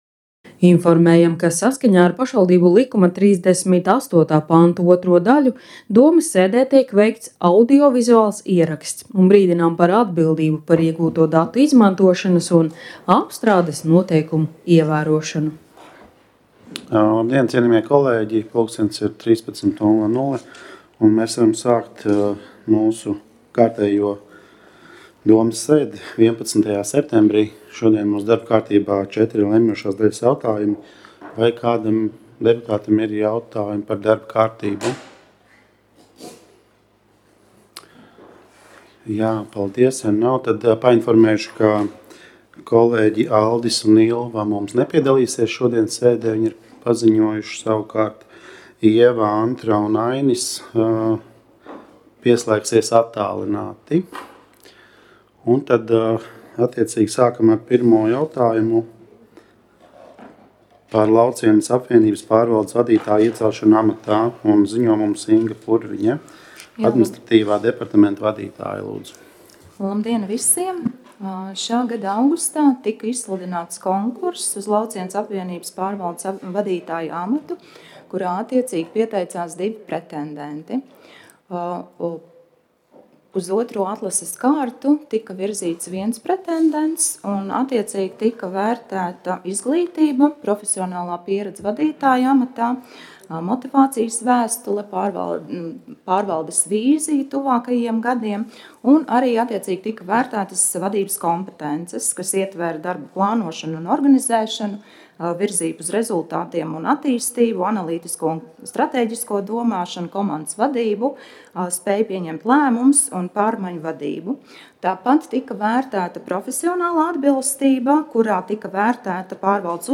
Domes sēdes audio